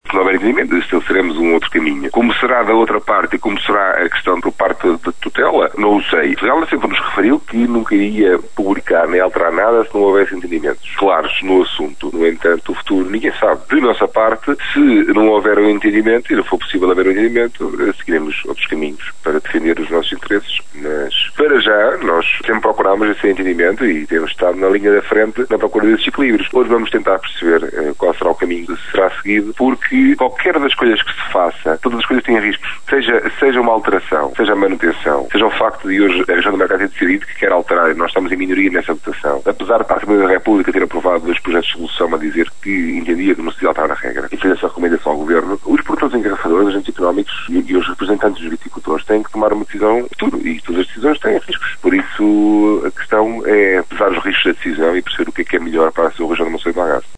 Em declarações à Rádio Caminha